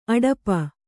♪ aḍapa